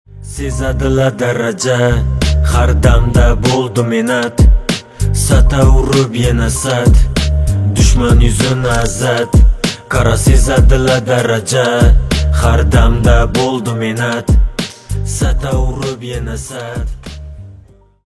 восточные на казахском